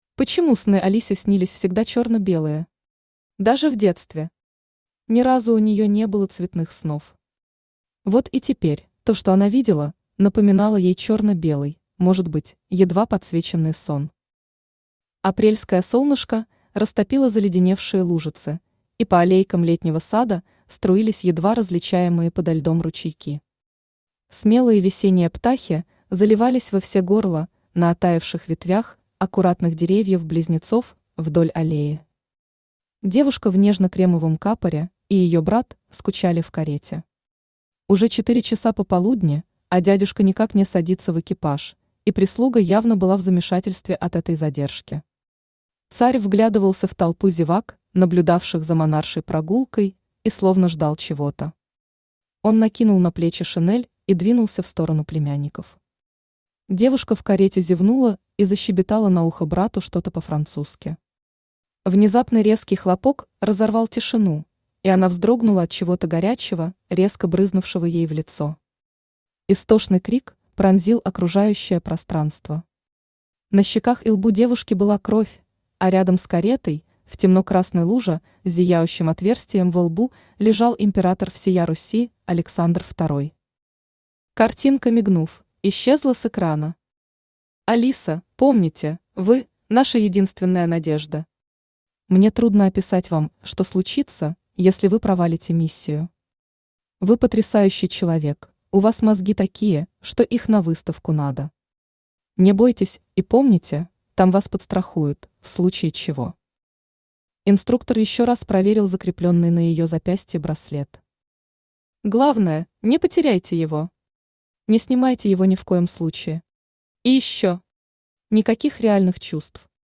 Спасение государя российского Автор Лариса Черногорец Читает аудиокнигу Авточтец ЛитРес.